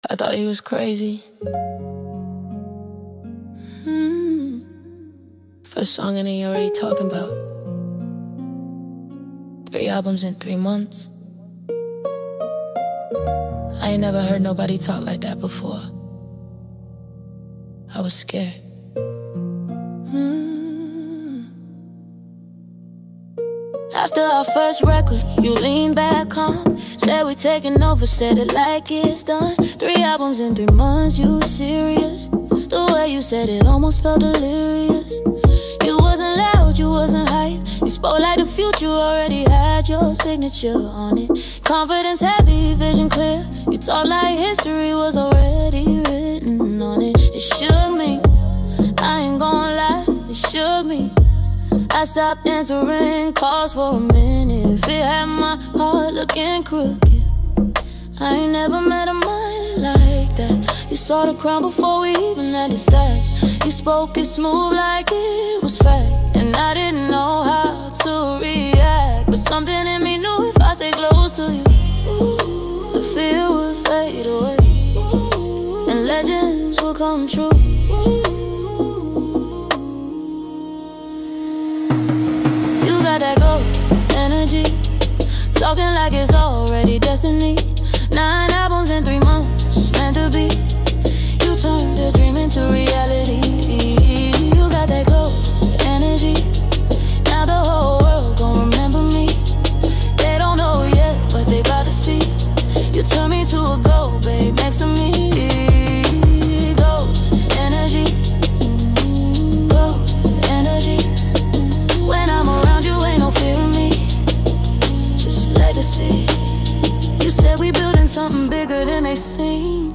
blending rhythm, soul, storytelling, and modern sound